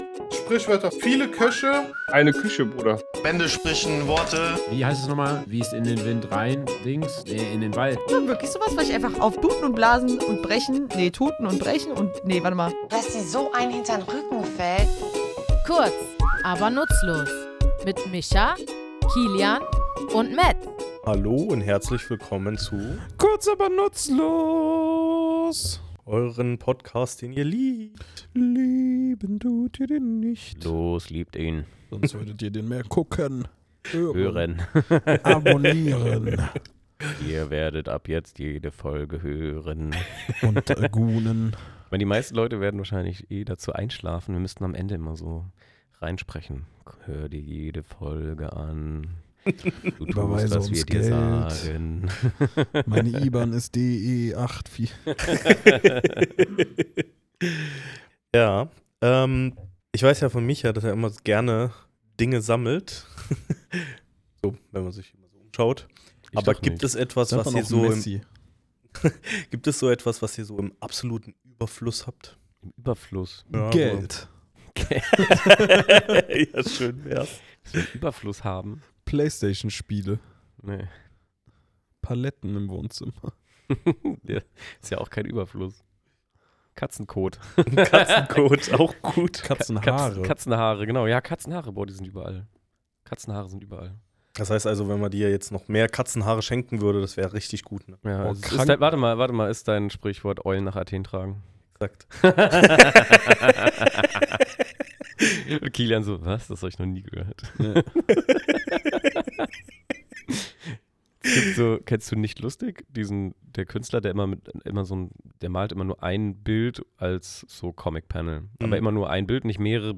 Wir, drei tätowierende Sprachliebhaber, tauchen in unserem Tattoostudio tief in die Geschichte dieser Redensart ein. Sie stammt aus dem alten Griechenland, wo die Eule das Symbol der Göttin Athene war – und Athen nicht nur voller Eulen, sondern auch voller Silbermünzen war, die ebenfalls Eulen zeigten.